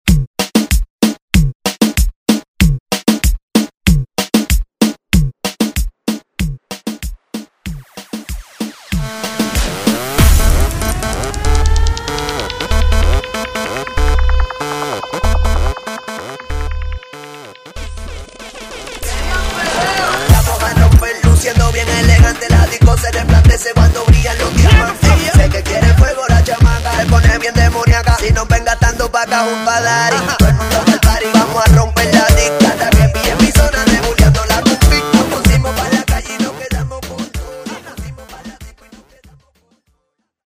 perfect for DJs and Latin music lovers. dj remixes